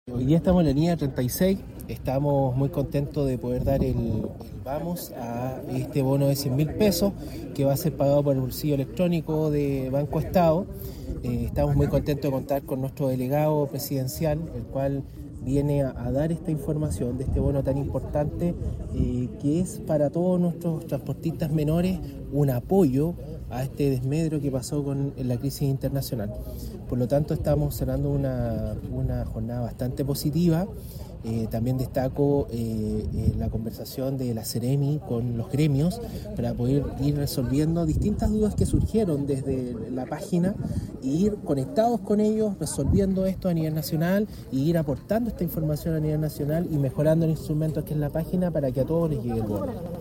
BONO-TRANSPORTISTAS-Seremi-Marcelo-Rojas.mp3